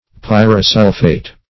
Pyrosulphate \Pyr`o*sul"phate\, n.